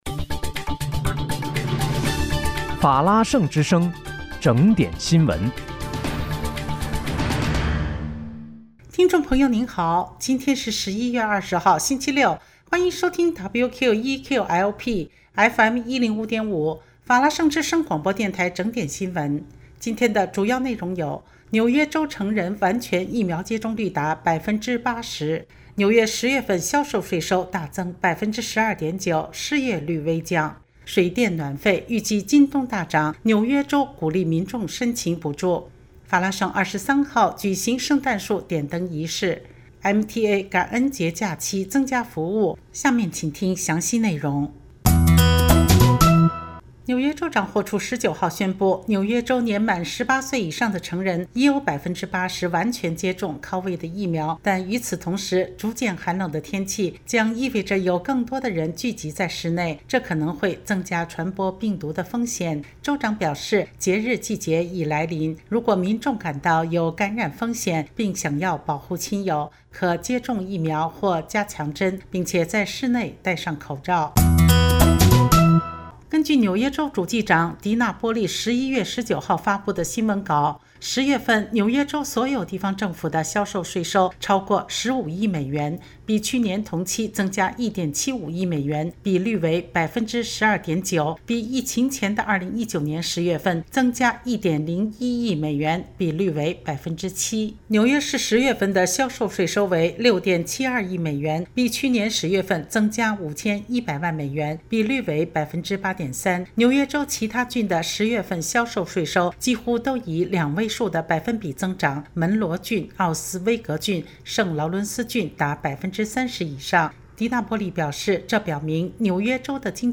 11月20日（星期六）纽约整点新闻